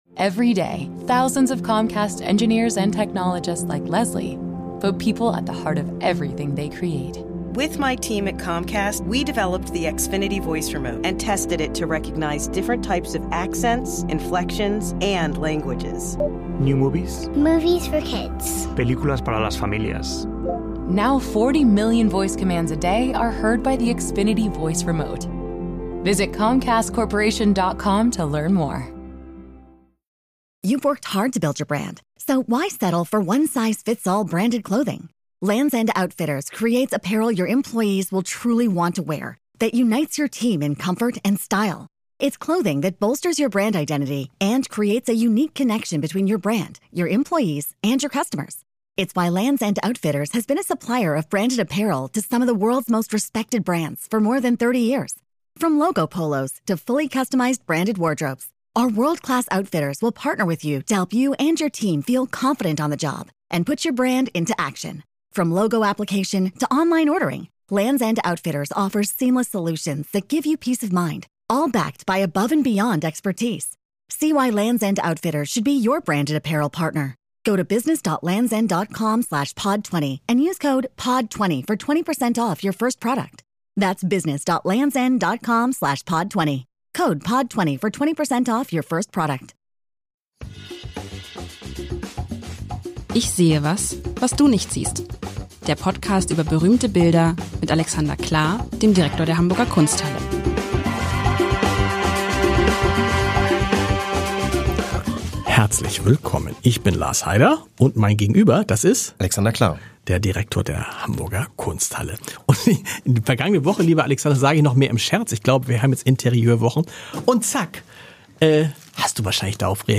Unsere beiden Moderatoren freuen sich auf Ihre Fragen, auf Ihr Feedback und Ihre Anregungen.